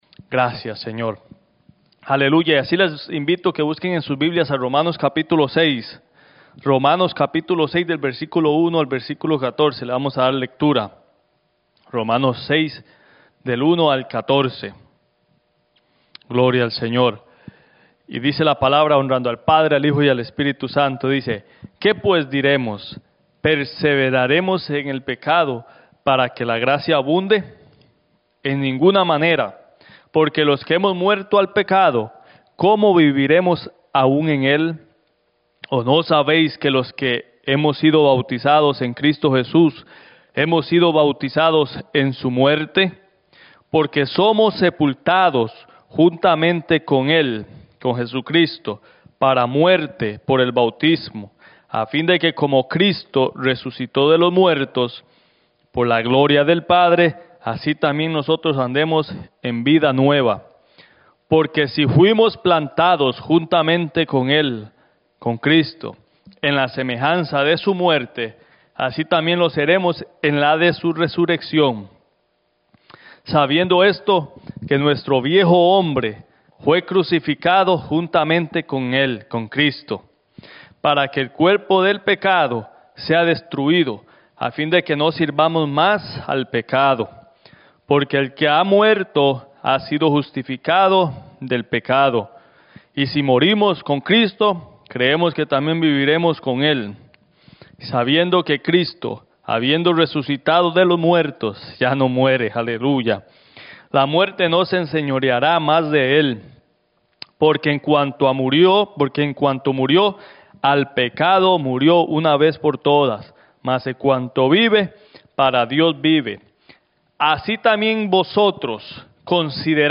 Mensaje
en la Iglesia Misión Evangélica en Souderton, PA